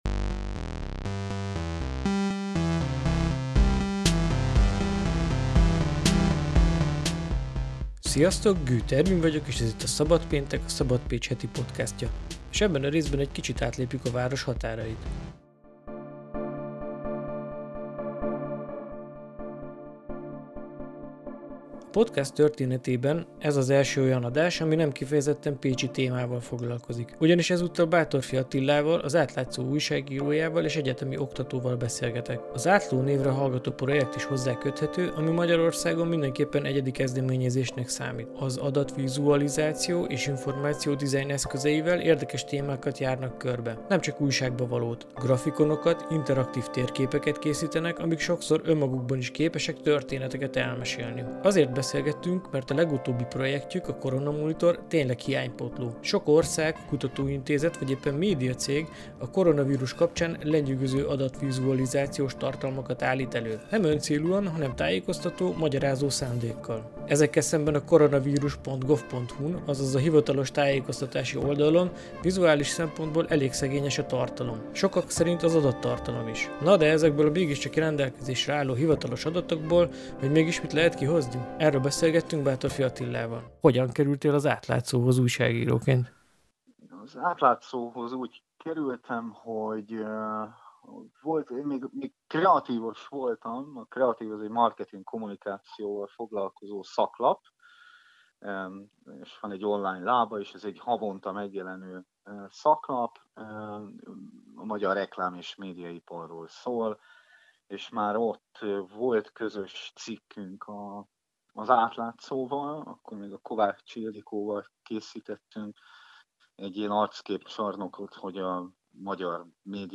Interjú és vélemény.